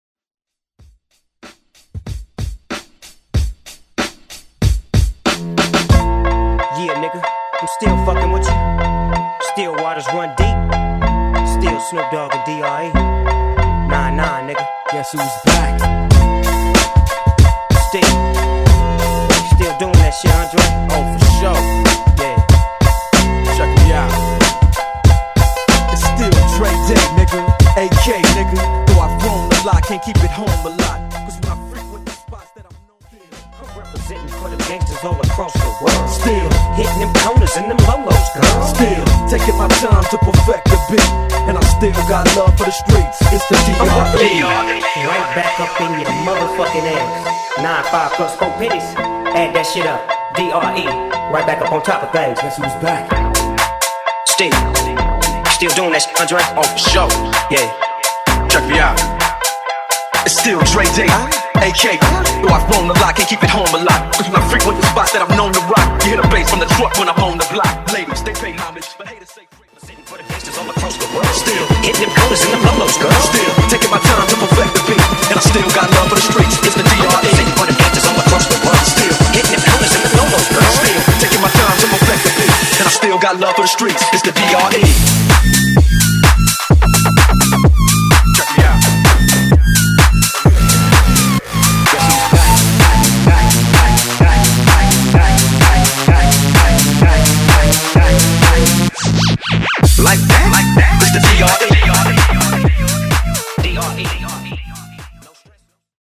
Genres: BOOTLEG , EDM , MASHUPS
Clean BPM: 128 Time